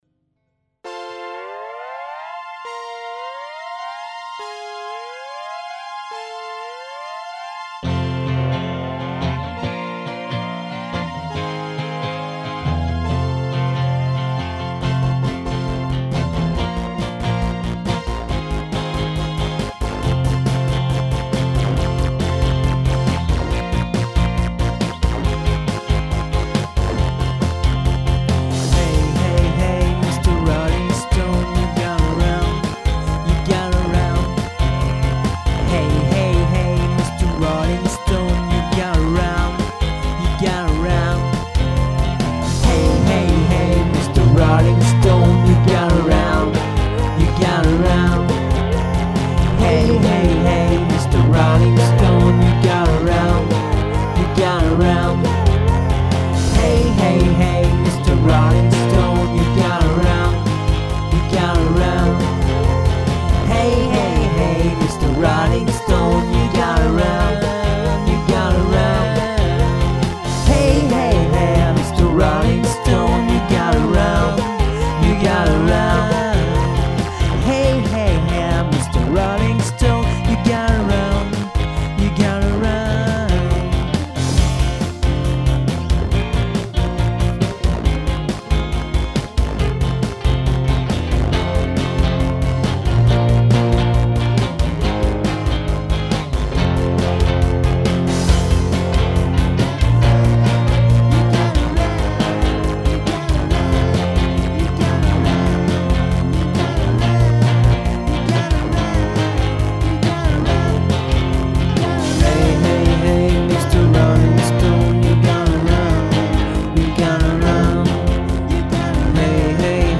Boah, c'est que des bouts de trucs, une simple base de travail, avec une batterie midi pourrie (pas bossé dessus)... rien de défintif... mais puisque tu demandes :mrg:
En fait j'aime beaucoup l'ambiance seventies qui se dégage de ces morceaux.
pour moi aussi grand moment de rock'n'roll